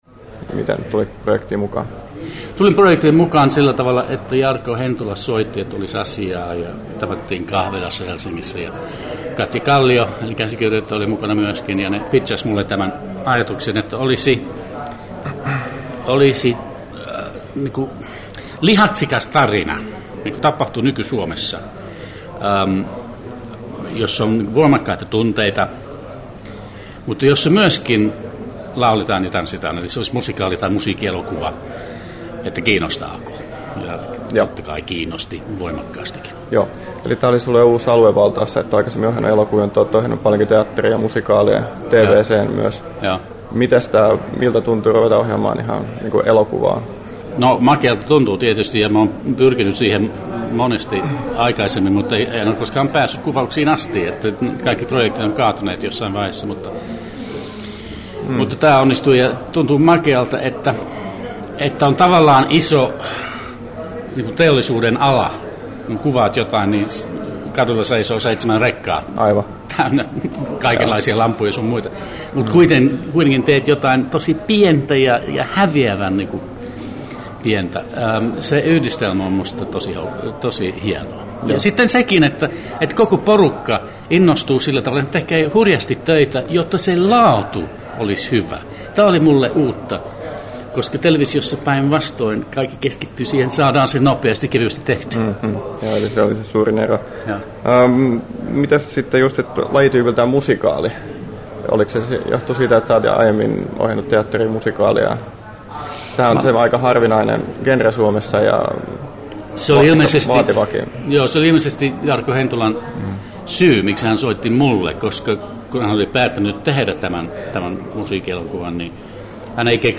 Neil Hardwickin haastattelu Kesto: 8’19” Tallennettu: 4.1.2010, Turku Toimittaja